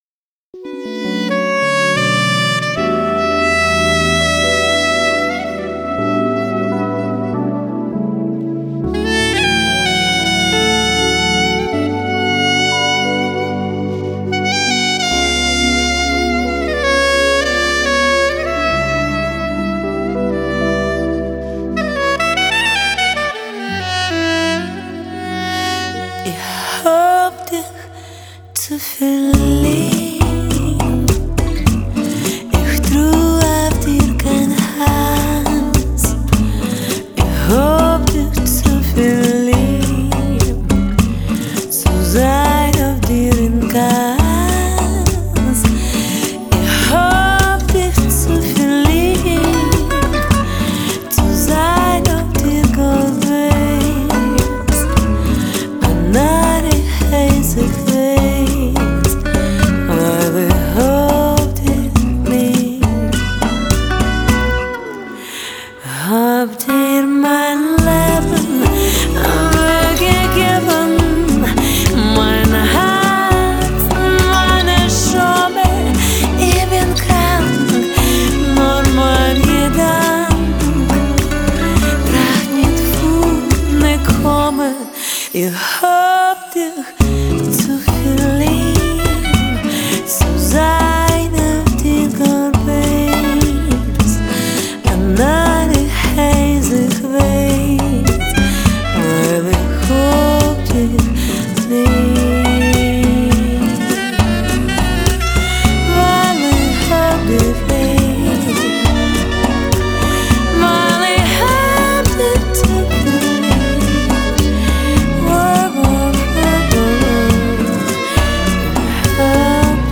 куда и вошло известное танго на идиш